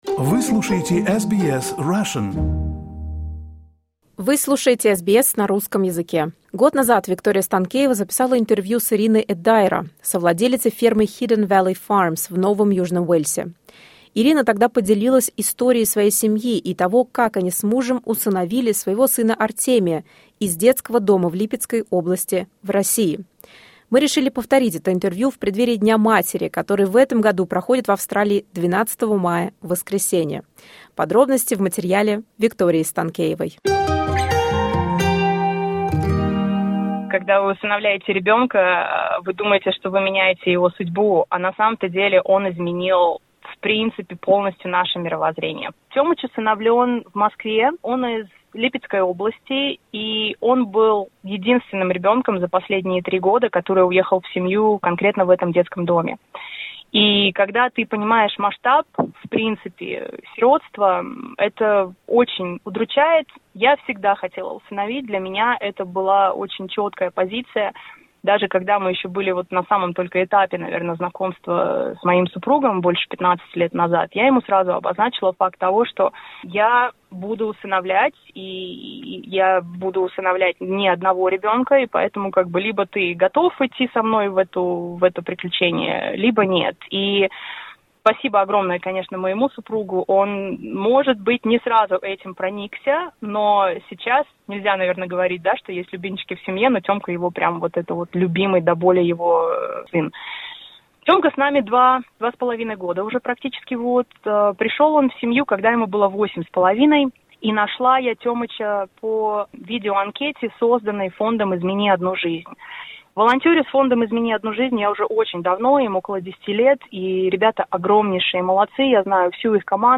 In Mother's Day, SBS Russian is repeating the archived interview from 2023